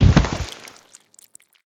pelt.ogg